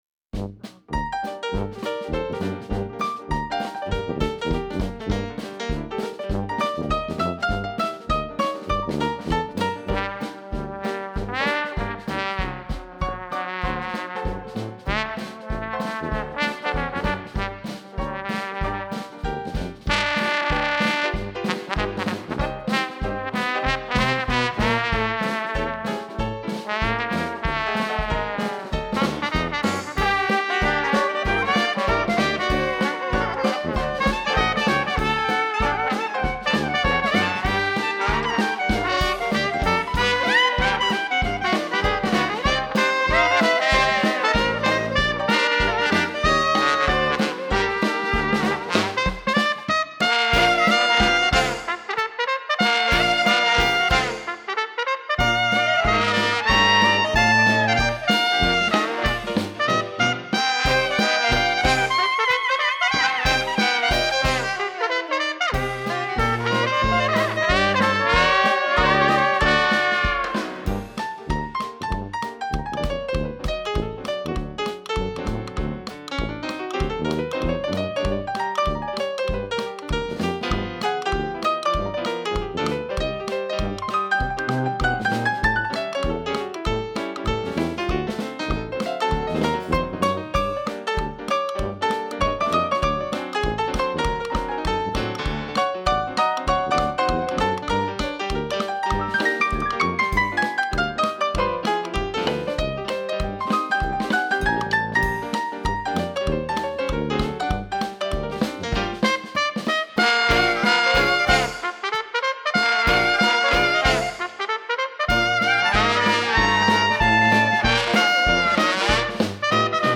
Всю неделю перед  праздником проводились различные музыкальные фестивали .
Вполне естественно, что я был на концерте Сибирского диксиленда.
тромбон
банджо
сакс
туба